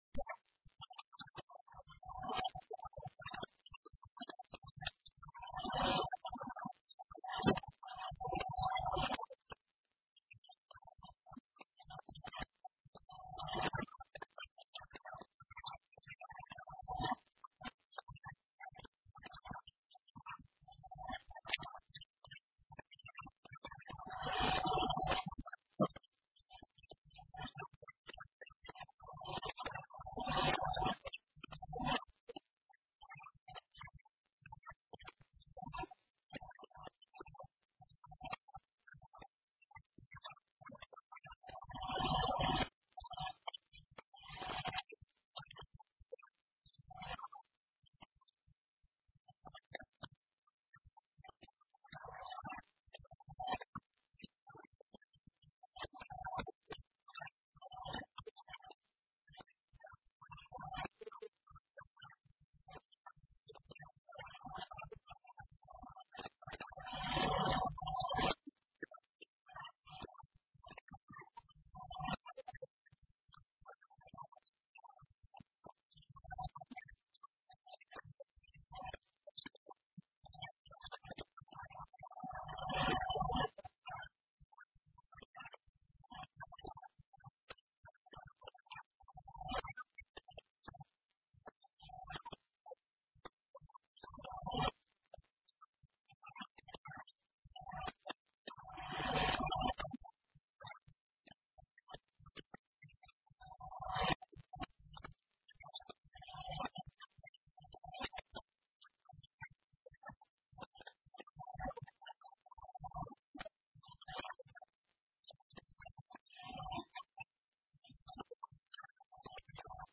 A Secretária Regional da Segurança Social afirmou hoje, na Assembleia Legislativa, na Horta, que o Governo dos Açores vai reformular o departamento da Ação Social com o objetivo de “melhorar ainda mais a resposta social” no arquipélago.
Piedade Lalanda falava na apresentação da proposta de decreto legislativo regional que cria o Instituto da Segurança Social dos Açores, procedendo à fusão do Instituto para o Desenvolvimento Social dos Açores e do Instituto de Gestão Financeira da Segurança Social nos Açores.